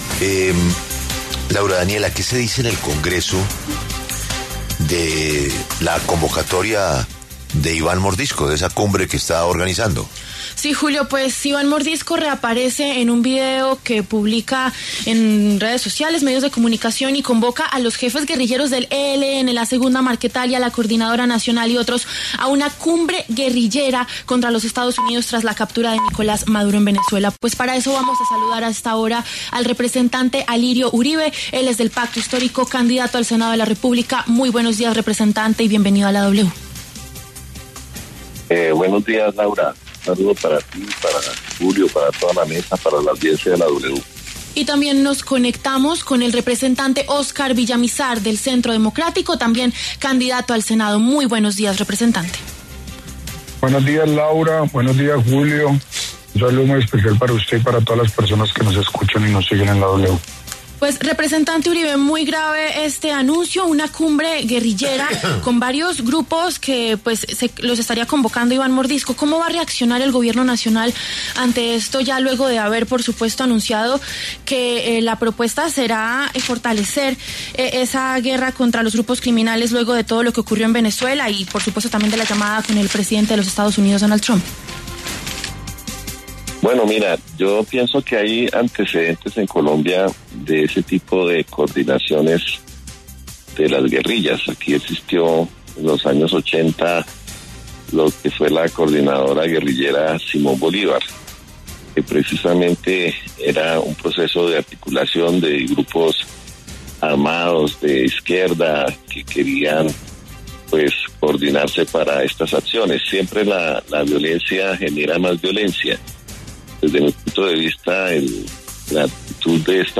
Debate: Oposición dice que Mordisco convoca cumbre guerrillera porque Trump “los tiene acorralados”
Los representantes Alirio Uribe, del Pacto Histórico, y Óscar Villamizar, del Centro Democrático, pasaron por los micrófonos de La W. Desde el Centro Democrático aseguraron que el gobierno de Donald Trump tiene a los grupos criminales “acorralados”.